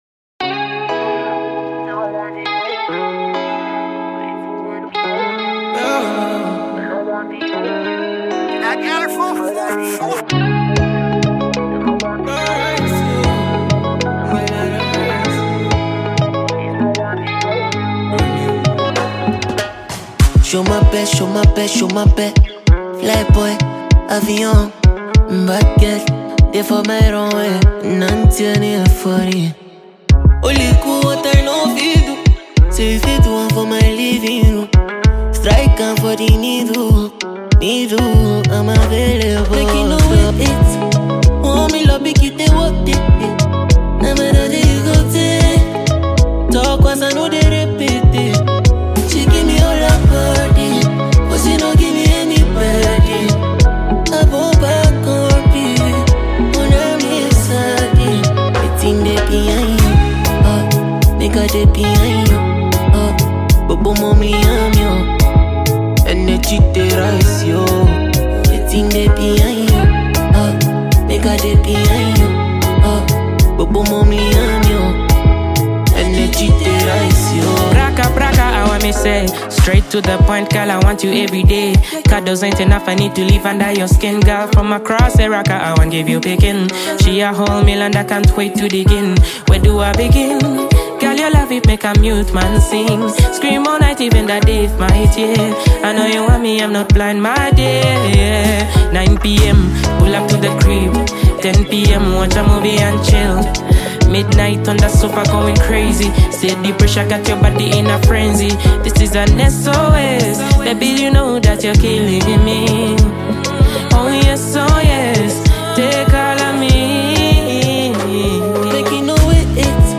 Ghanaian afrobeat musician and singer